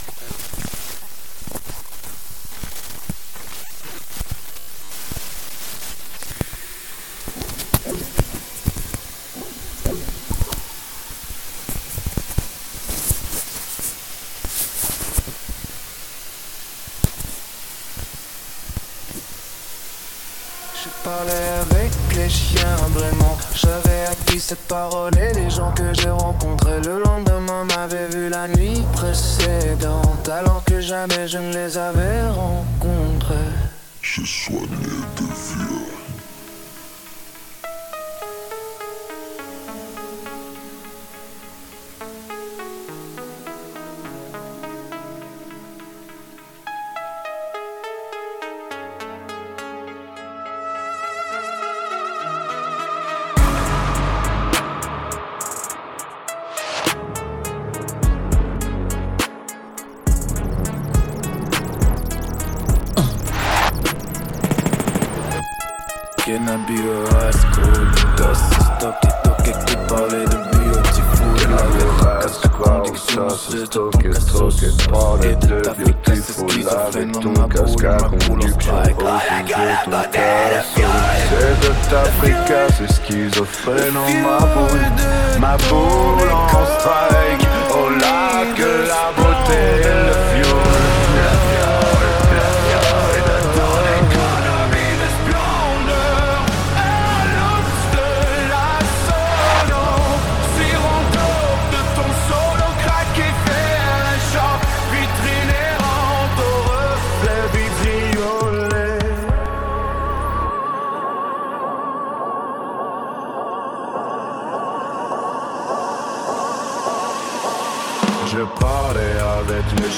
claviers, chant
guitare, chant, chœurs
basse, chœurs
synthétiseur, chœur et ingénieur du son-bruitiste